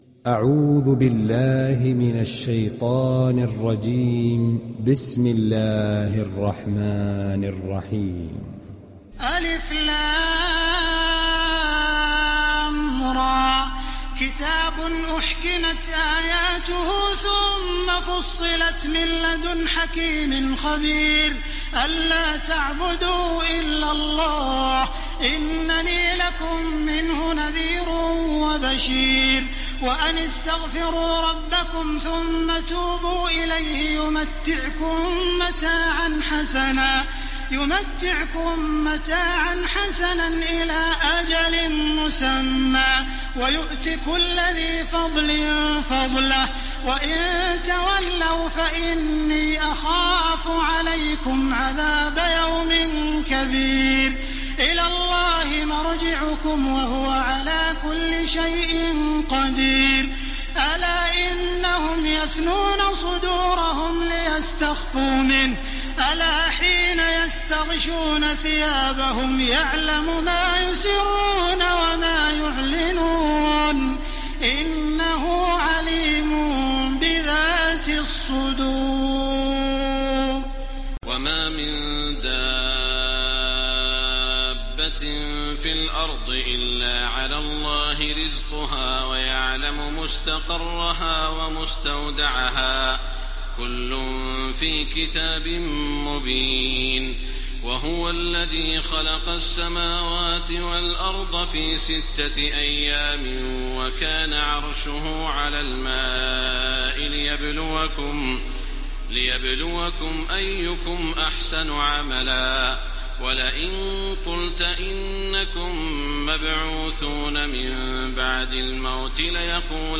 دانلود سوره هود mp3 تراويح الحرم المكي 1430 روایت حفص از عاصم, قرآن را دانلود کنید و گوش کن mp3 ، لینک مستقیم کامل
دانلود سوره هود تراويح الحرم المكي 1430